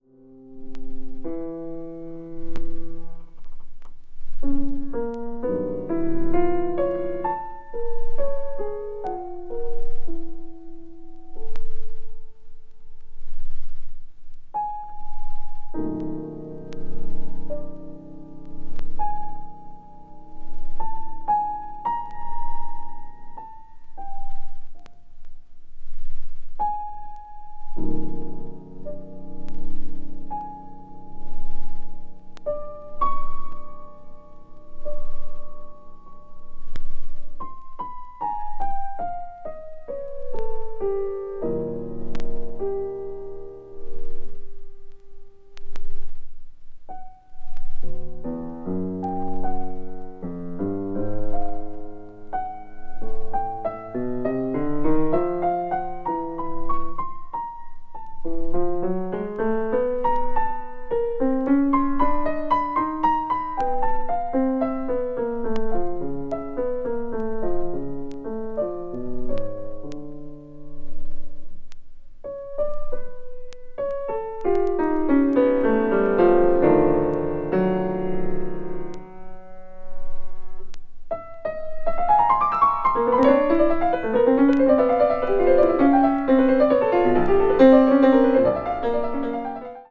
media : EX-/EX-(わずかにチリノイズが入る箇所あり)